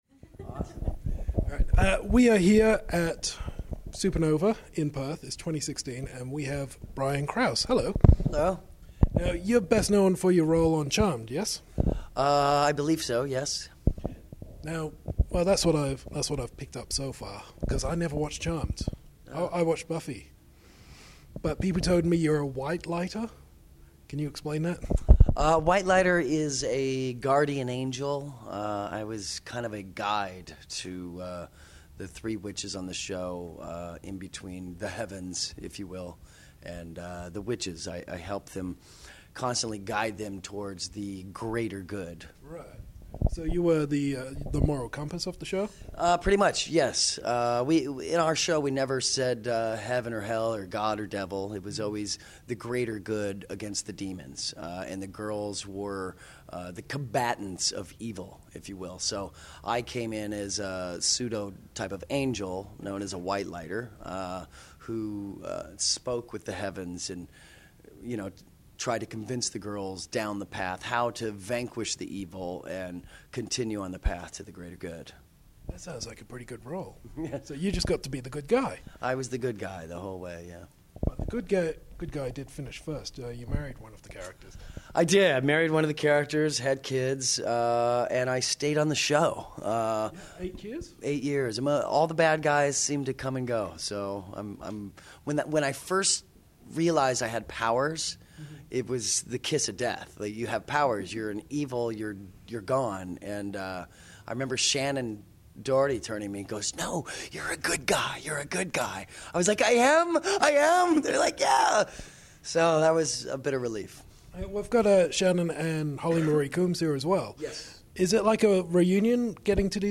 We’re returning to Supanova, where we’ve already brought you interviews with comic writer Tom TaylorBuffy star James Marsters and the X-Men from Deadpool. Now we’ve got the man who brought the white light into the lives of the Charmed witches, Brian Krause.
brian-krause-interview.mp3